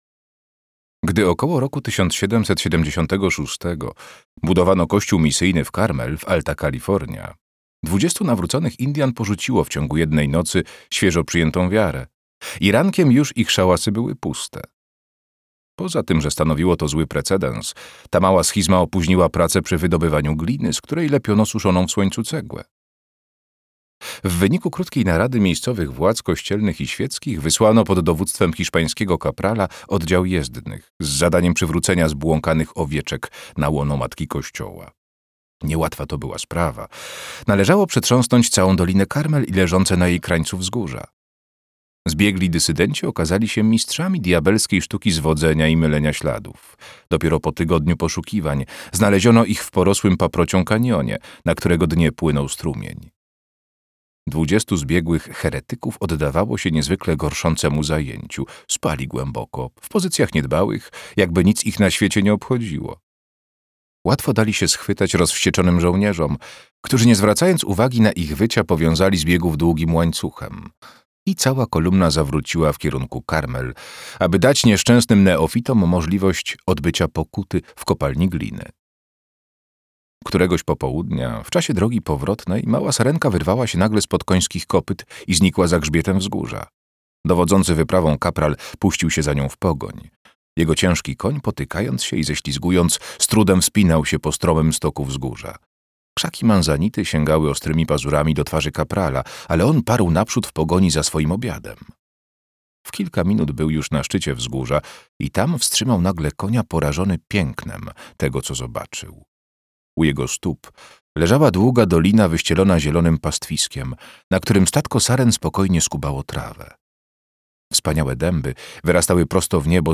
Pastwiska Niebieskie - John Steinbeck - audiobook